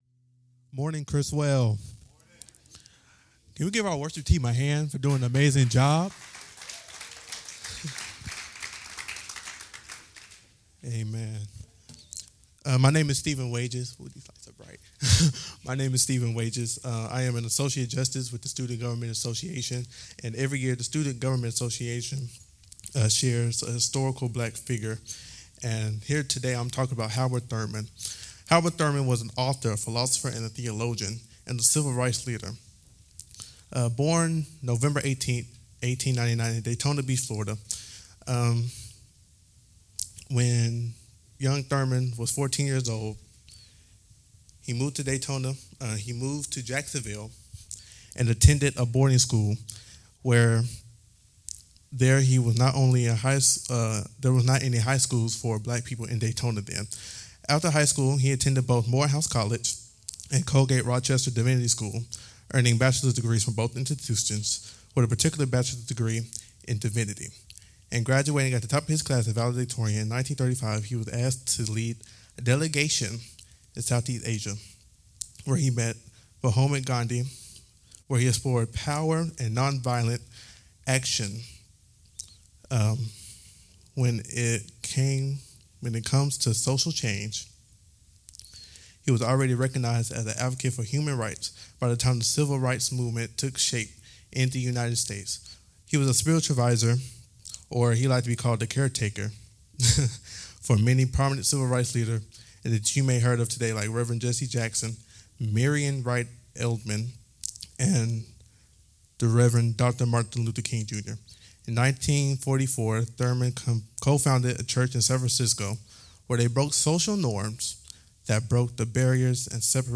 Criswell College Chapel Service.